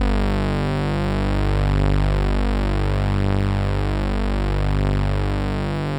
G2_trance_lead_1.wav